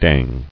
[dang]